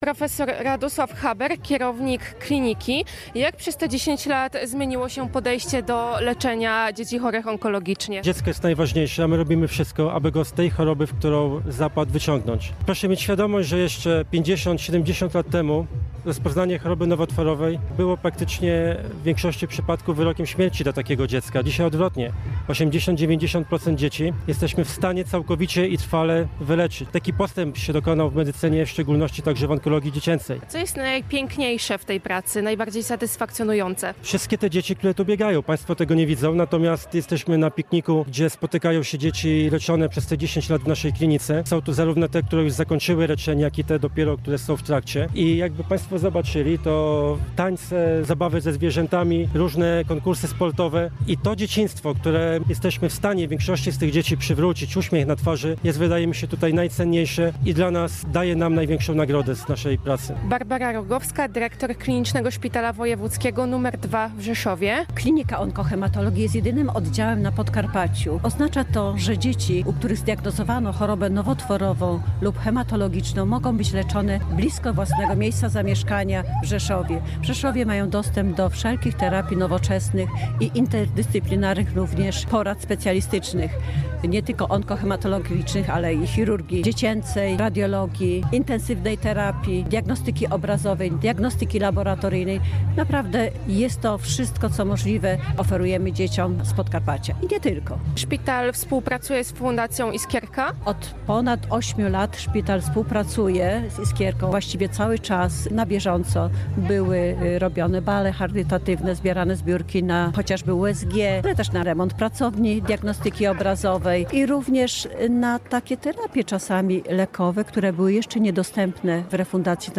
Relację